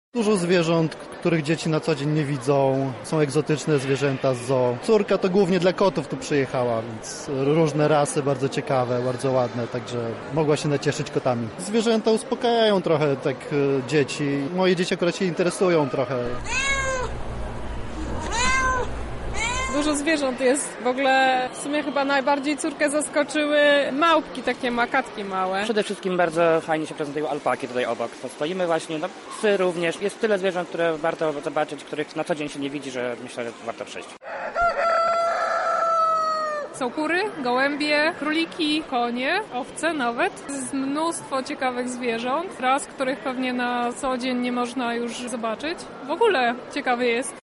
Odwiedzający Targi Lublin wyjaśnili dlaczego wybrali akurat taki sposób na spędzenie weekendu: